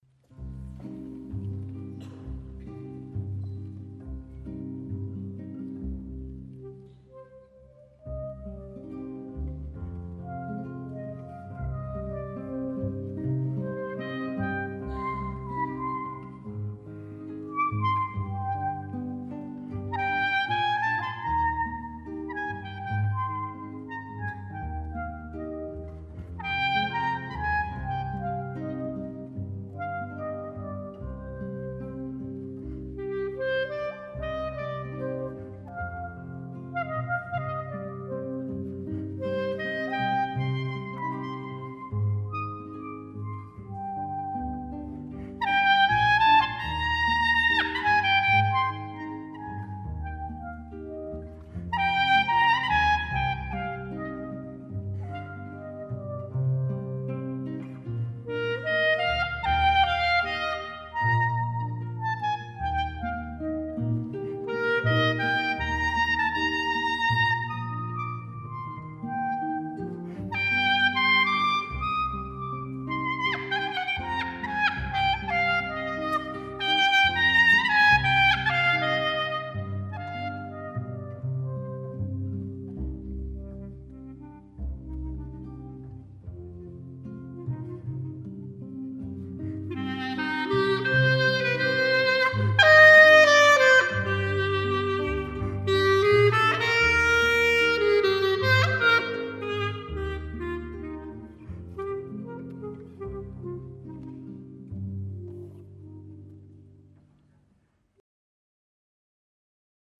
Bз-за кулис выходит девушка (цветочница из 1-й сцены) и трогательно поет.